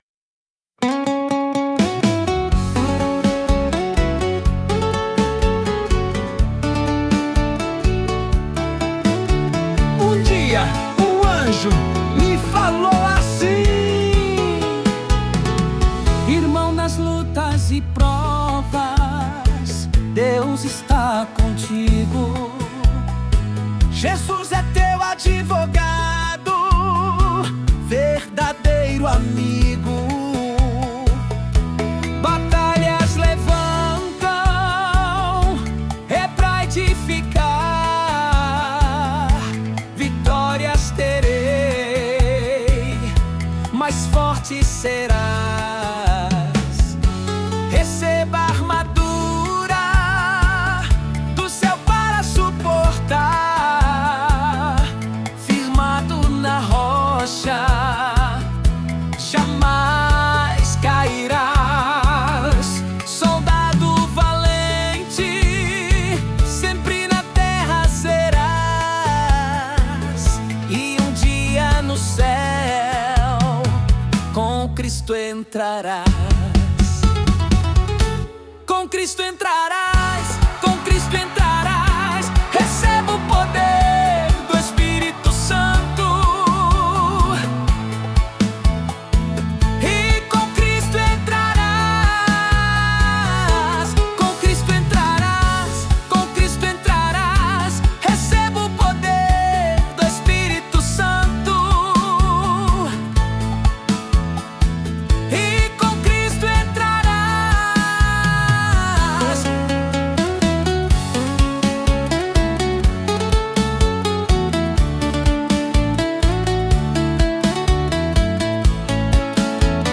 [Vocal Masculino]